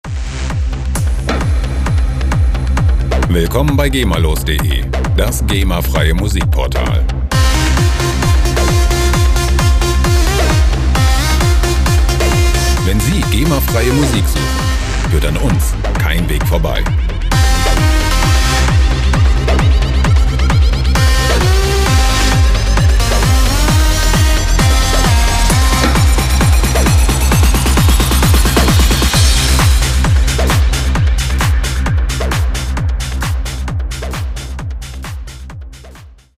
freie Techno Loops
Musikstil: Techno
Tempo: 132 bpm